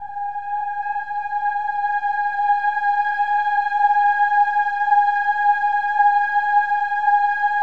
OH-AH  G#5-R.wav